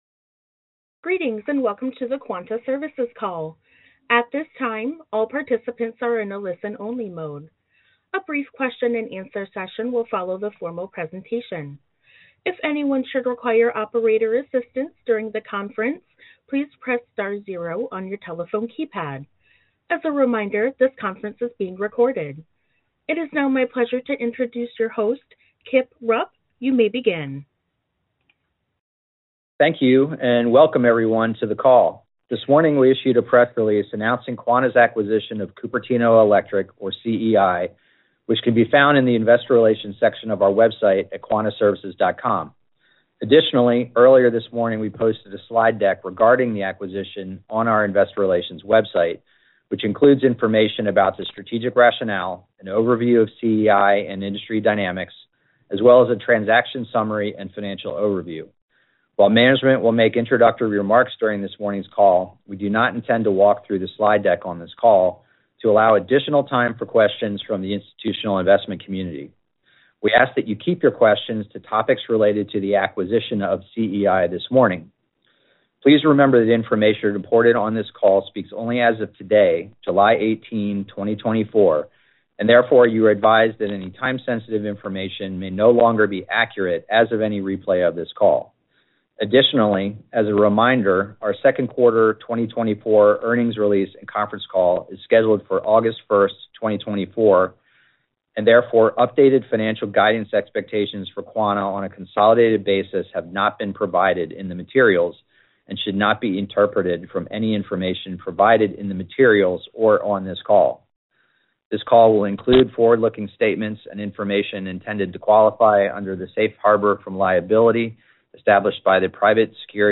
Quanta’s Acquisition of Cupertino Electric Conference Call